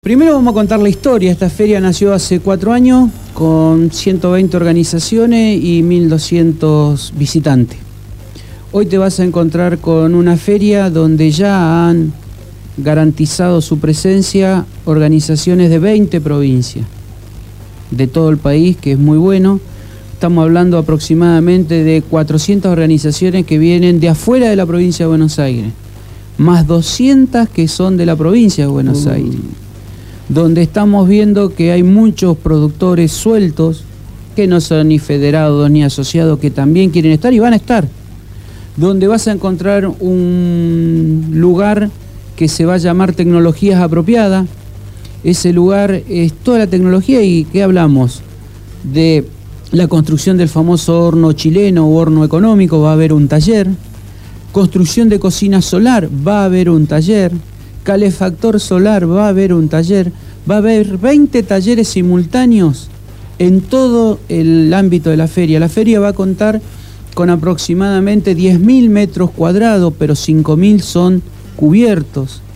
estuvo en el estudio de la Gráfica.
Entrevistado